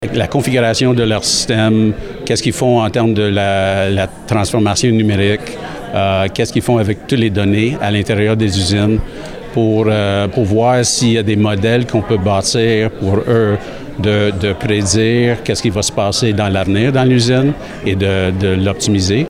Contrôles Laurentide, un fournisseur de solutions d’automatisation a annoncé jeudi en conférence de presse, la construction d’un nouveau centre dans la zone entrepreneuriale à Bécancour.